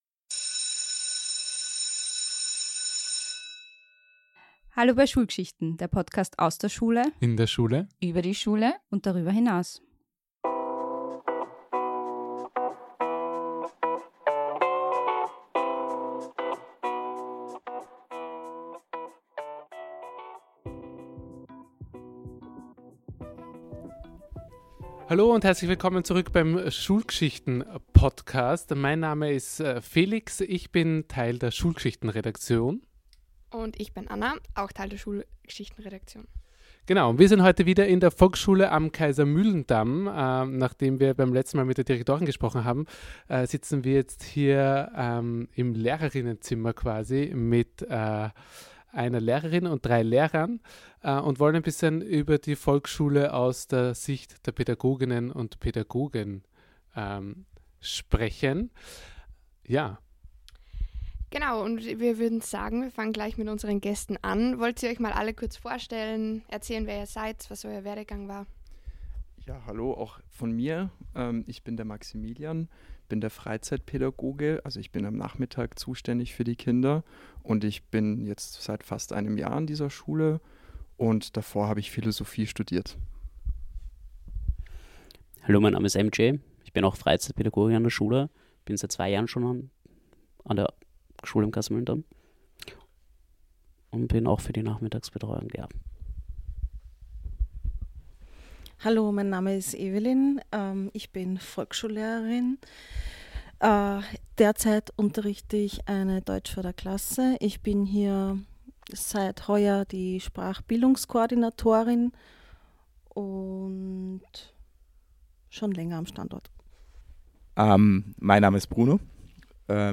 Wir sind zu Gast im Lehrer:innen-Zimmer der Offenen Volksschule am Kaisermühlendamm.